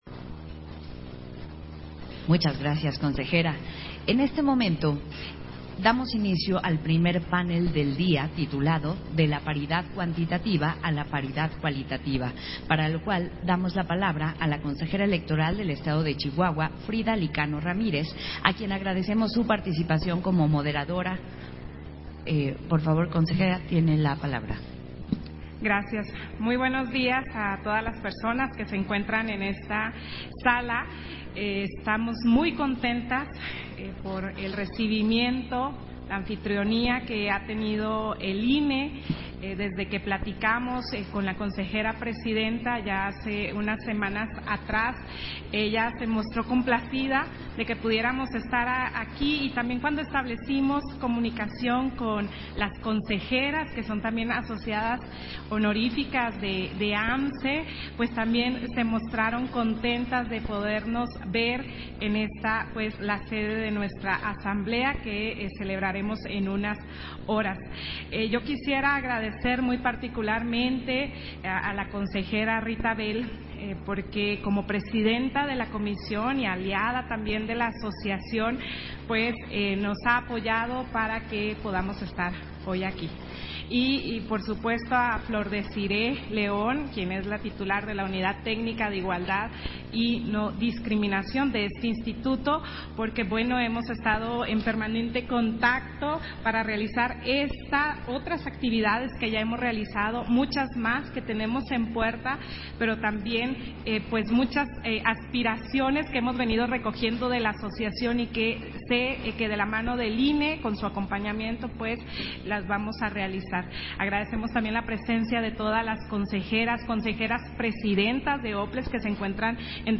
Versión estenográfica del panel 1, De la paridad cuantitativa a la paridad cualitativa, en el marco del Diálogo entre mujeres a una década de la paridad en México